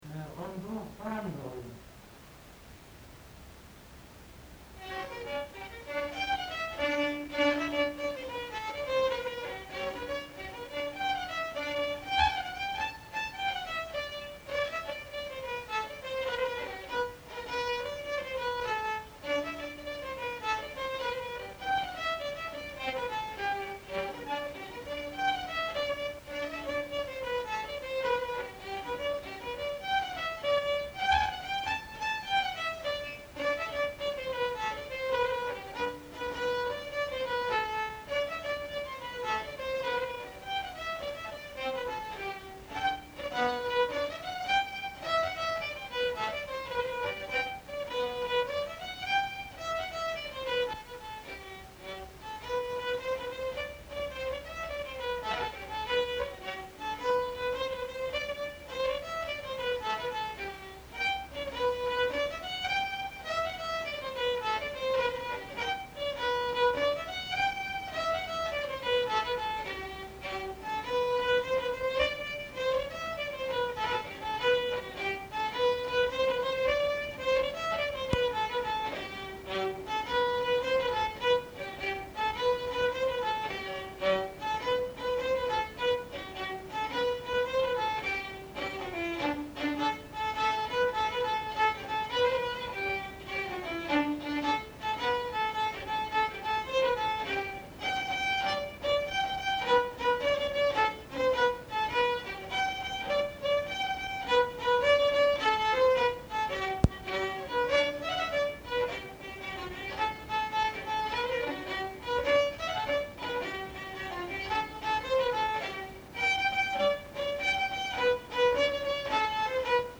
Aire culturelle : Agenais
Lieu : Auterive
Genre : morceau instrumental
Instrument de musique : violon
Danse : rondeau
Il interprète plusieurs mélodies.